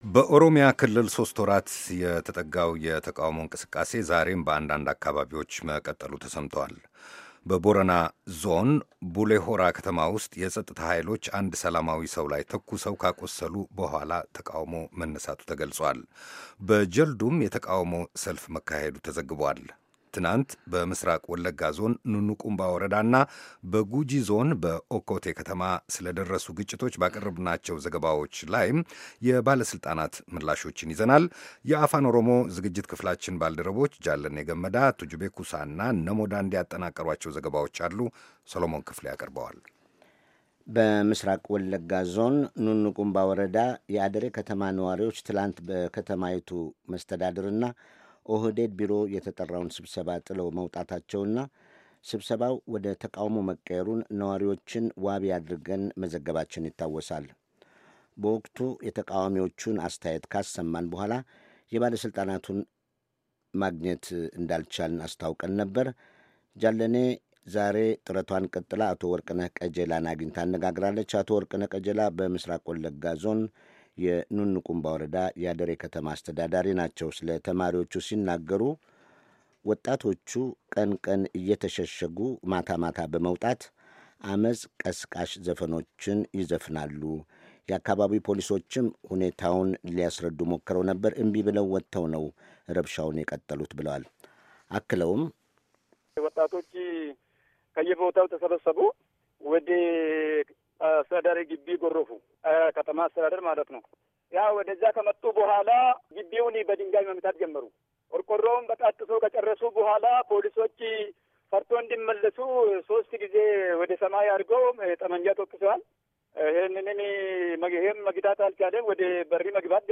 ወደ ተለያዩ ከተሞች ደውለው ያጠናቀሯቸው ዘገባዎች አሉ።